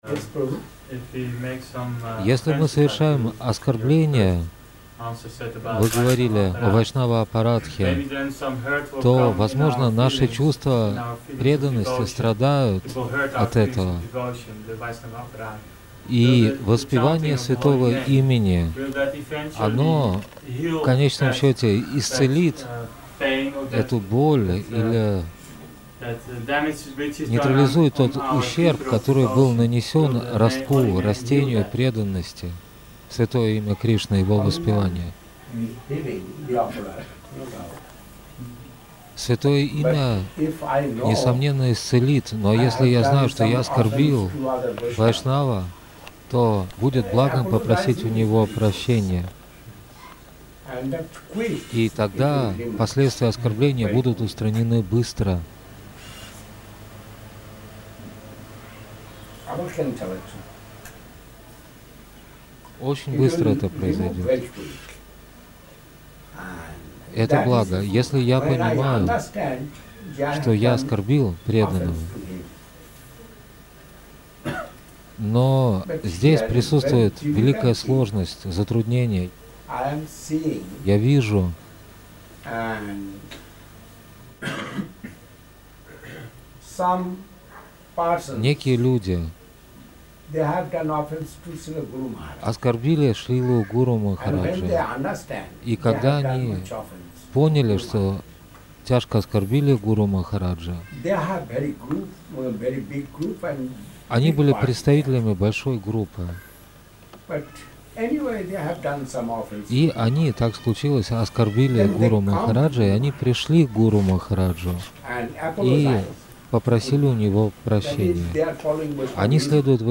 (Июль 1992 года. Лондон, Великобритания)
Istselenie_ot_oskorbleniya_vai_shnava_stereo_1992.mp3